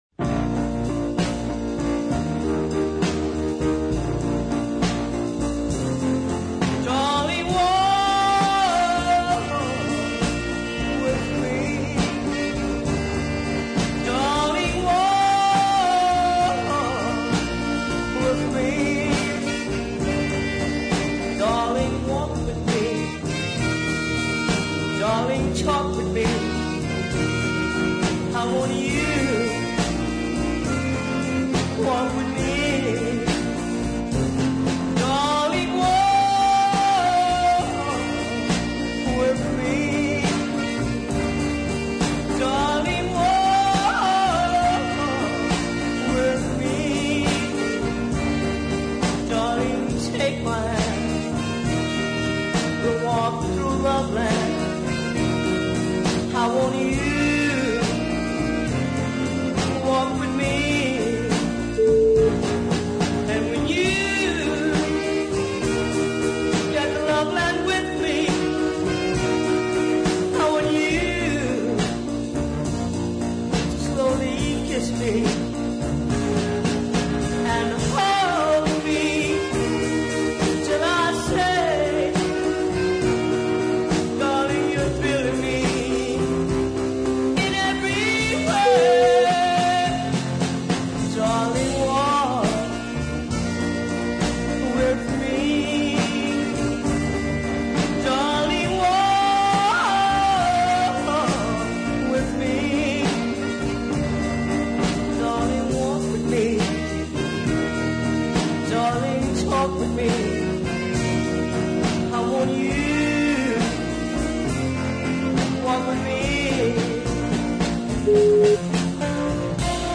But this single is a smashing example of early soul.